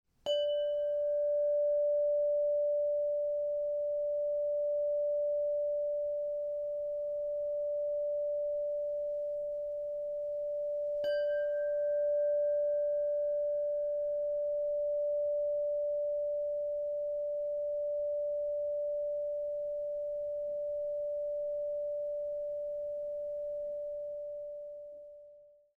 Délka zvonu je 49 cm.
Zvon tvoří hliníková trubice.
Zvuková ukázka závěsný zvon 586 Hz (mp3, 610 kB)